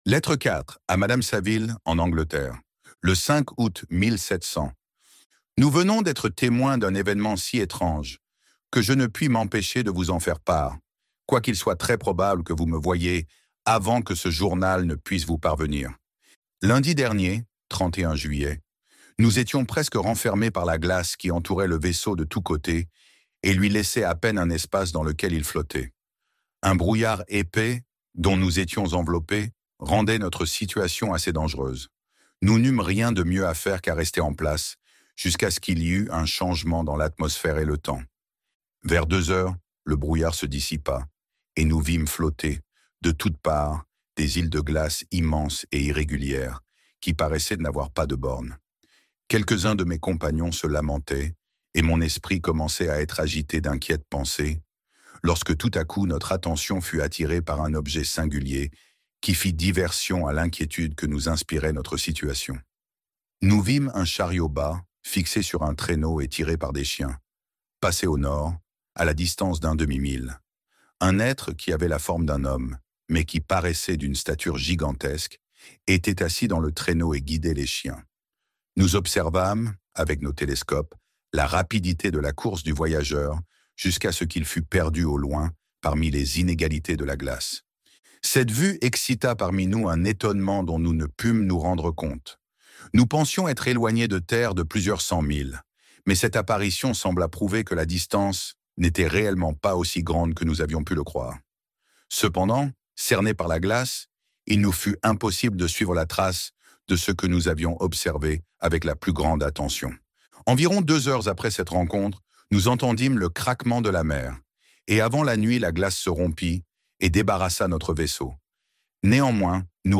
Frankenstein - Livre Audio